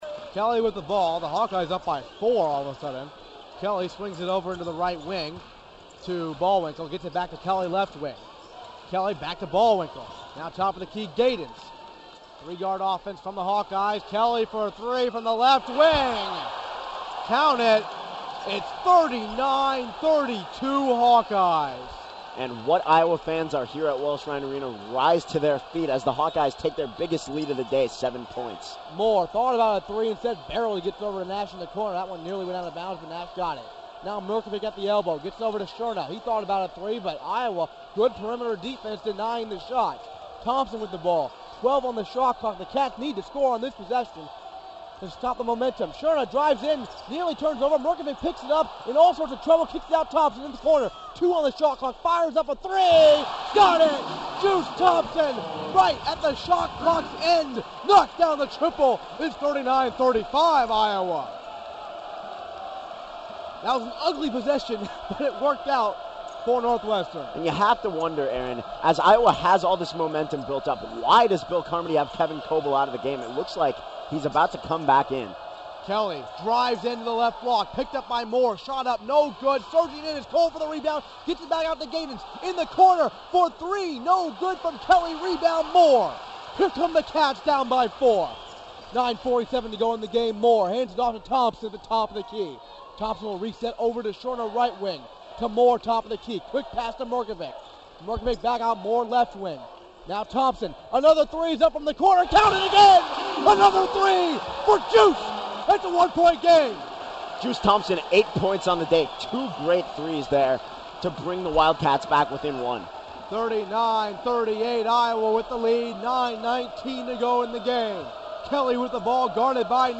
Calling Basketball
Basketball Sample
One of my last collegiate broadcasts was for Big Ten Network dot com and an excerpt follows:
basketball-sample.mp3